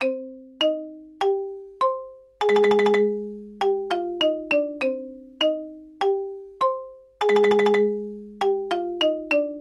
Звук хитрости в мультике на ксилофоне